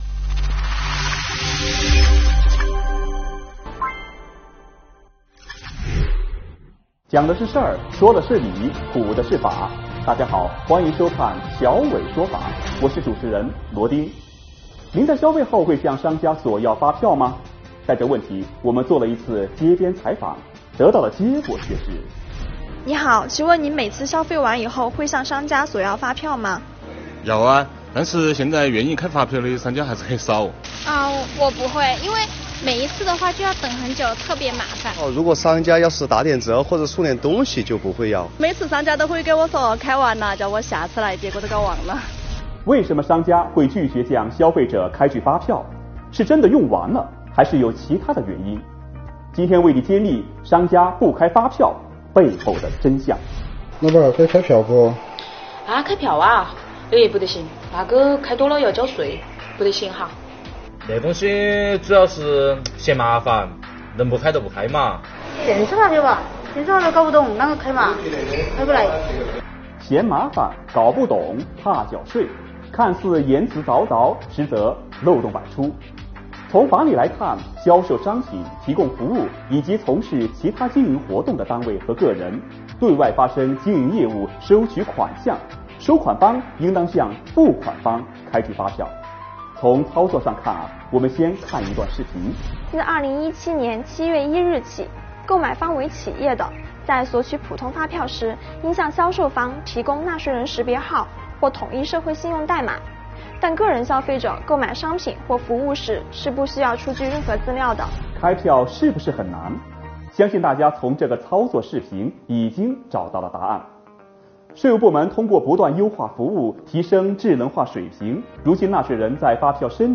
我们做了一次街边采访